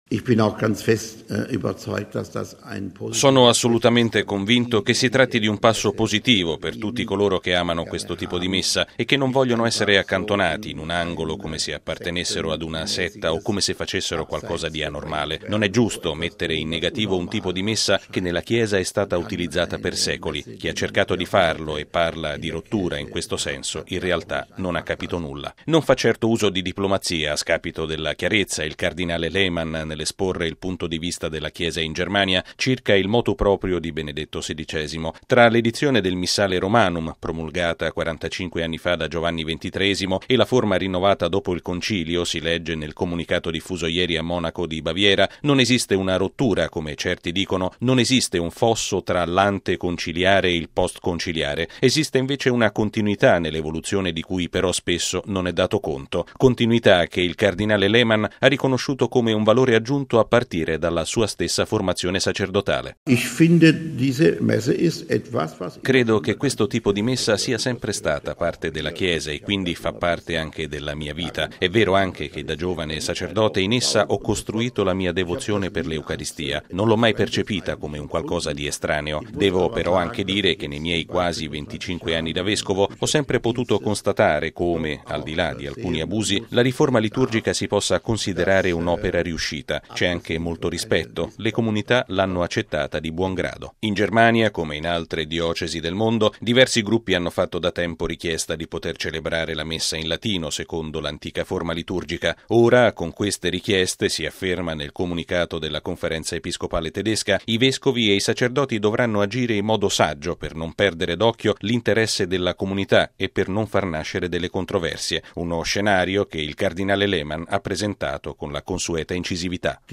Ieri a mezzogiorno - al momento in cui il Motu proprio veniva ufficialmente reso noto - il presidente della Conferenza episcopale tedesca, il cardinale Karl Lehmann, ha tenuto una conferenza stampa a Monaco di Baviera per esprimere la posizione della Chiesa locale sul documento.